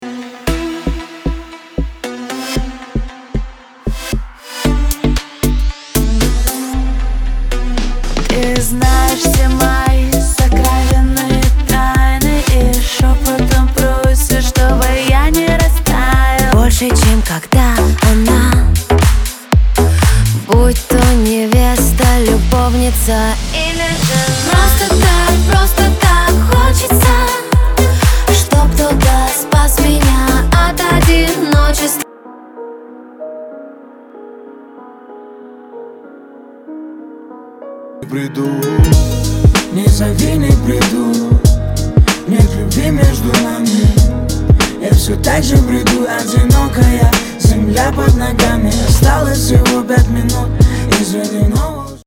Трек 3, (еще три разных отрывка) Тоже присутствует какой то объём, выходящий за мониторы. В третьем куске фано играет широко.